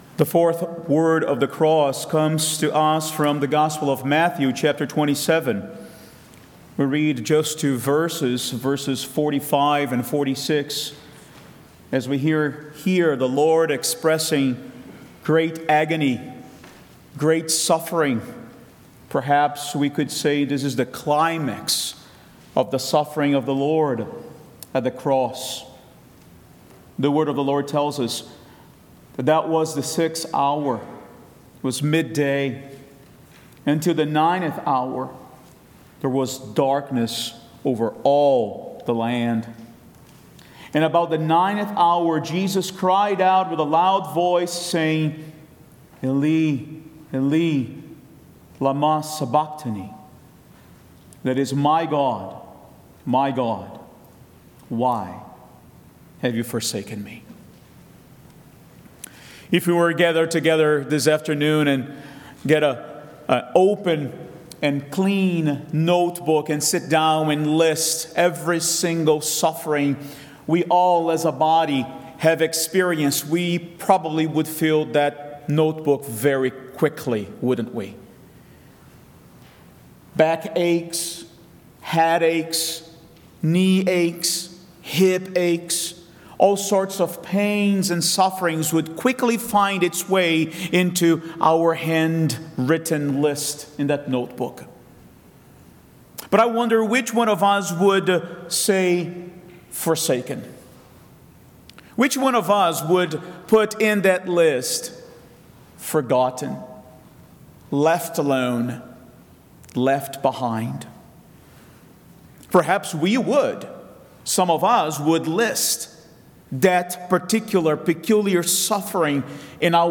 Community Good Friday 2025 4th Word